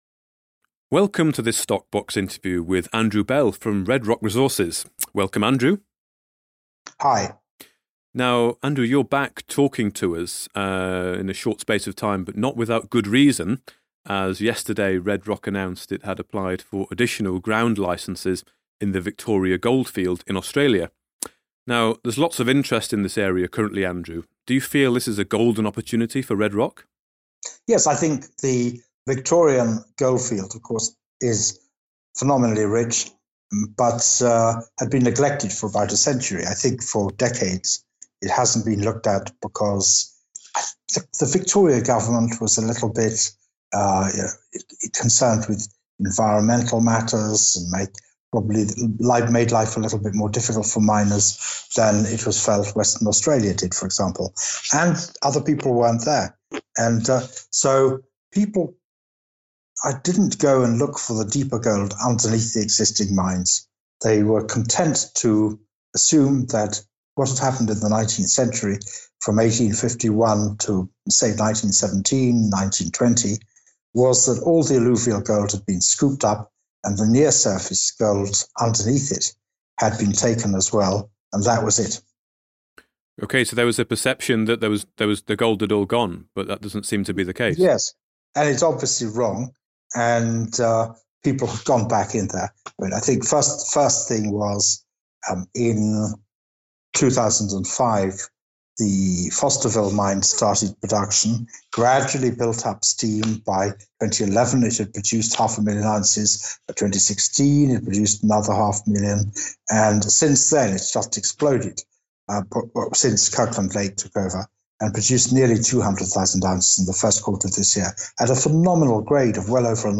StockBox Podcasts / Interview